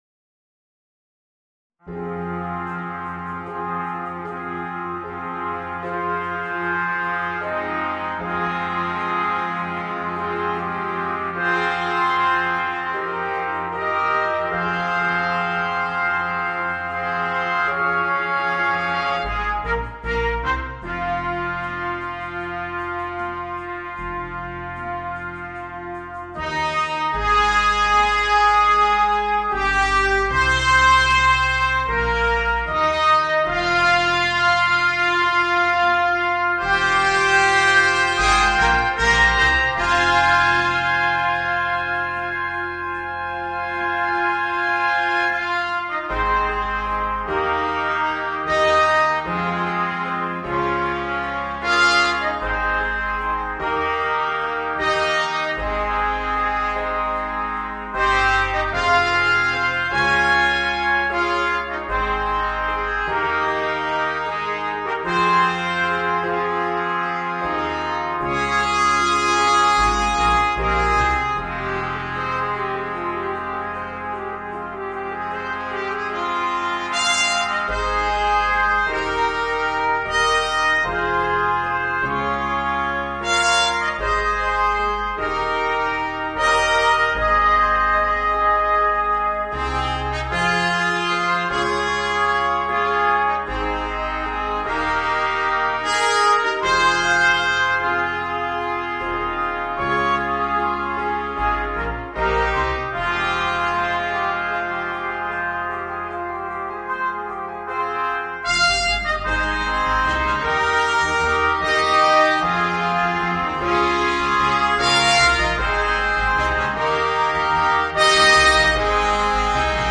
Voicing: 5 Trumpets and Piano